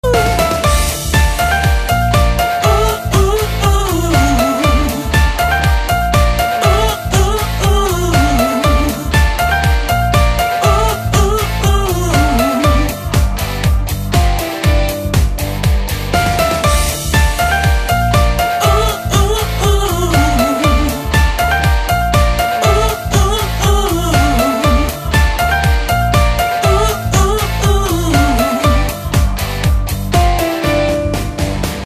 • Качество: 128, Stereo
поп
женский вокал
dance